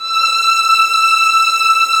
Index of /90_sSampleCDs/Roland L-CD702/VOL-1/STR_Vlns 6 mf-f/STR_Vls6 mf%f St